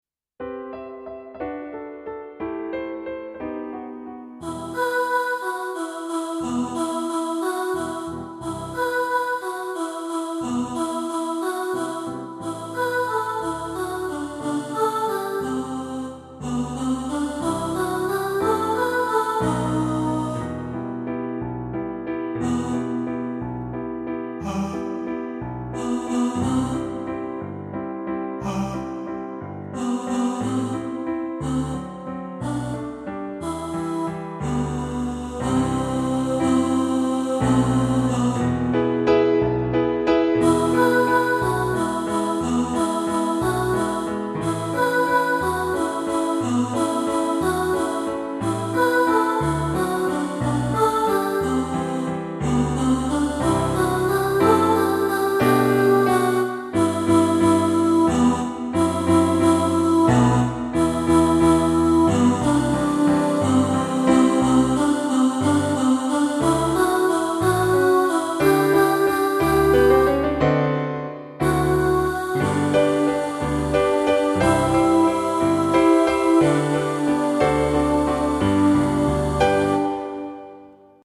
My-Favourite-Things-Alto.mp3